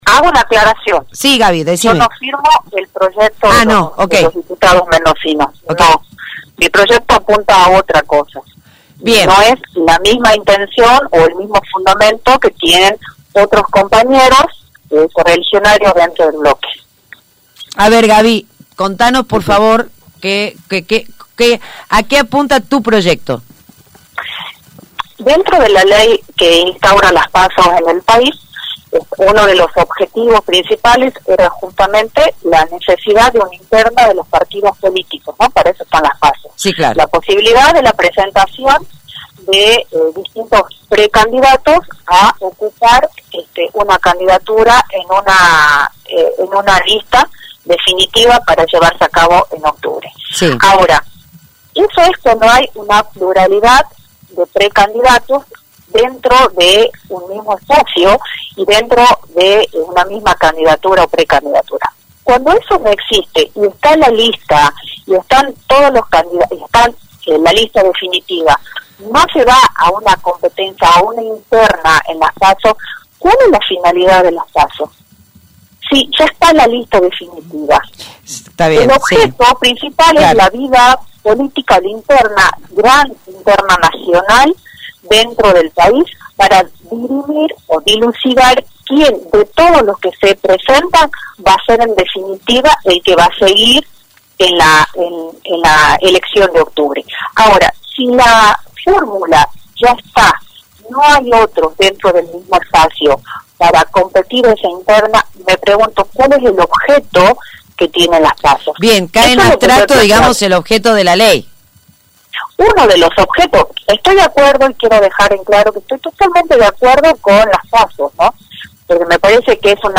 La diputada nacional Gabriela Burgos (FCJ)se refirió al proyecto que presentarán hoy dos diputados mendocinos – Sebastián Bragagnolo y Omar de Marchi- con el objeto de suspensión de las PASO del mes de agosto. La legisladora aclaró que no firmó el proyecto y que presentará otro que pretende modificar la ley anterior, pero con cara a las elecciones del 2021.
25-GABRIELA-BURGOS-Diputada-nacional-x-la-UCR.mp3